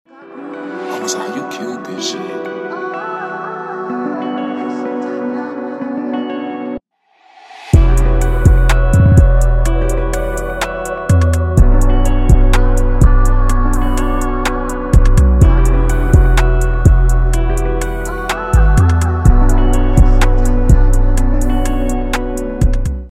#808s